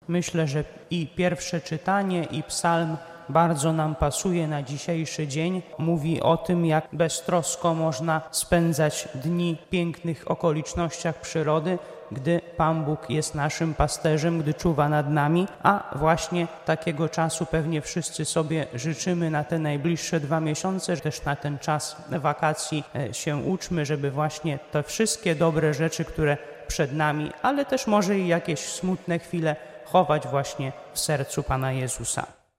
Łomża: Msza Święta na zakończenie roku szkolnego 2024/2025
W piątkowe (27.06) przedpołudnie w Łomżyńskiej Katedrze sprawowana była uroczysta Msza św. na zakończenie roku szkolnego 2024/2025 , w której wzięli udział uczniowie z łomżyńskich placówek, nauczyciele oraz rodzice.
kazanie.mp3